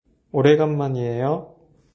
ネイティブの発音を沢山聞いて正しい読み方を覚えましょう。
오래간만이에요 [オレンガンマニエヨ]